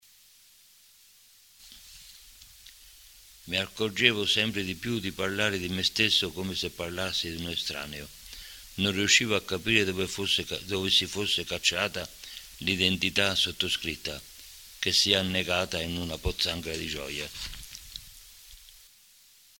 La registrazione è del 2004 ed è stata effettuata dai ragazzi dell’IPC di Cupra Marittima e dai loro insegnanti per Dream Radio Stream, la loro web radio, fu la prima in Italia di un istituto d’istruzione superiore. Le letture riguardano delle brevi poesie e alcuni aforismi.